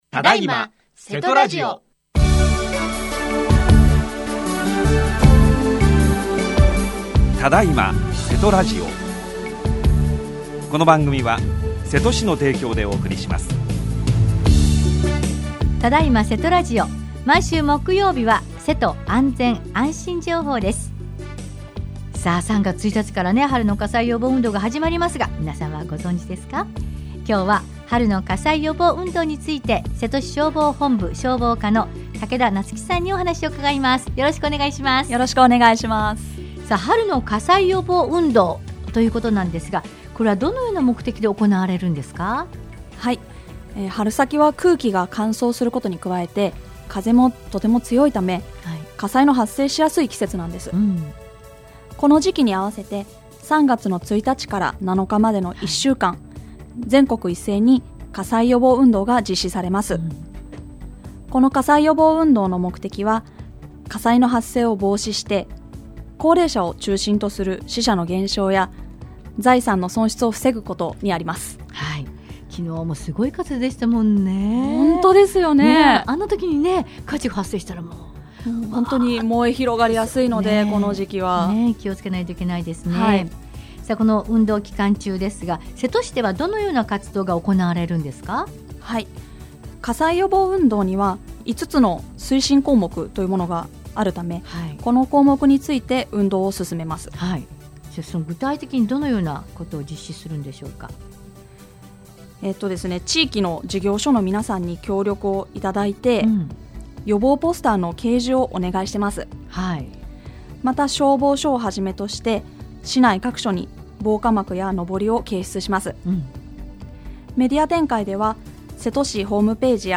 28年2月25日（木） 毎週木曜日は、せと安全・安心情報です。 3月1日から「春の火災予防運動」が始まります。